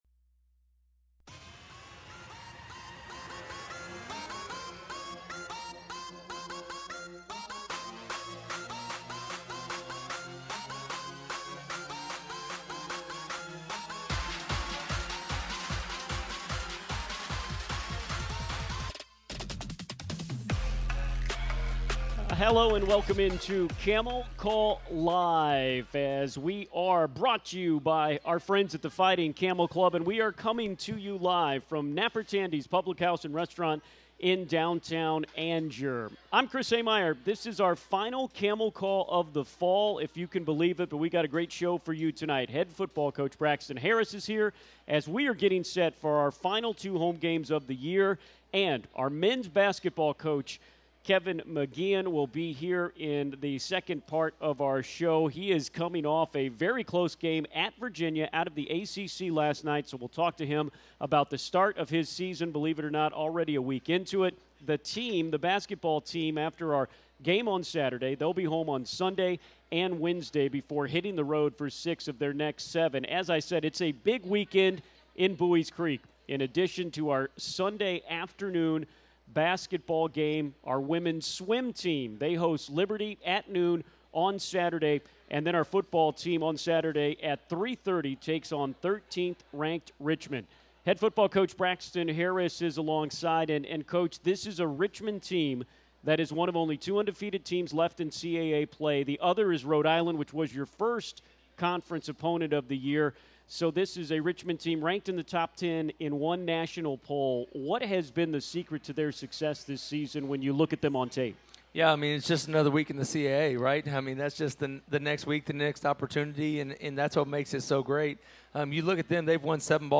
It's the final Camel Call Live of the Fall recorded at Napper Tandy's in downtown Angier.